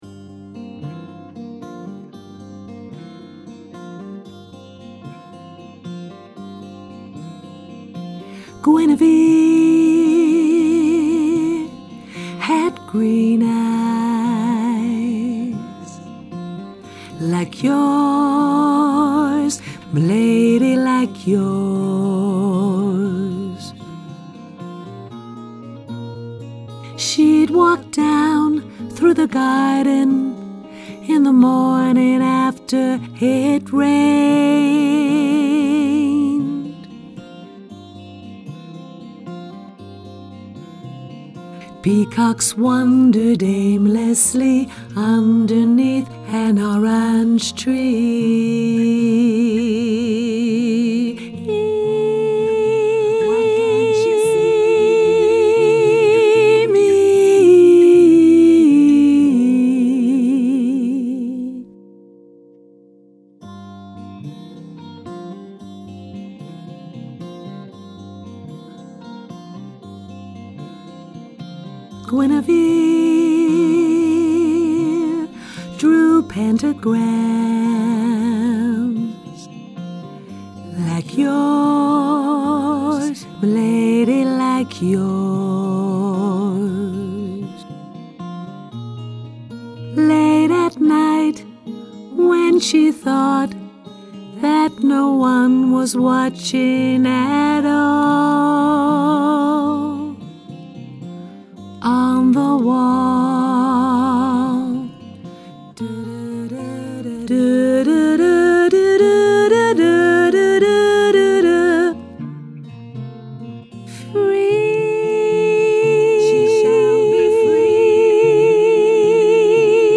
sopraan